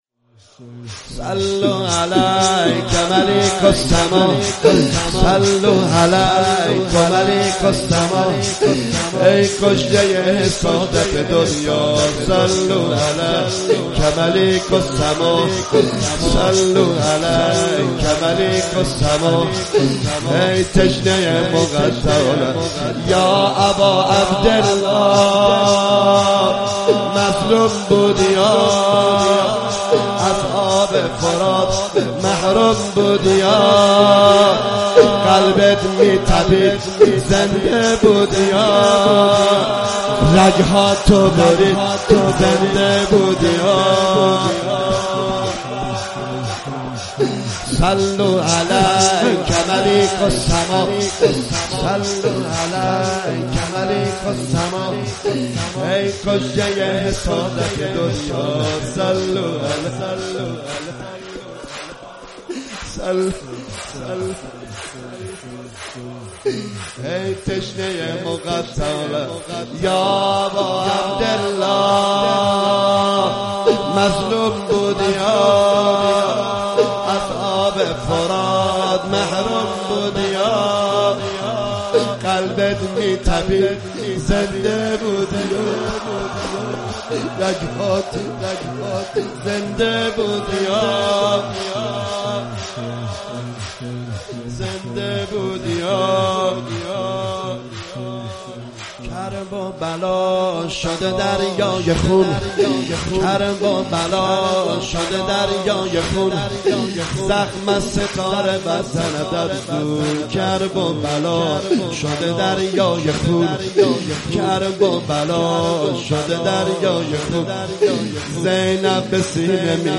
صوت مداحی های شب ششم محرم سال ۱۳۹۷
شور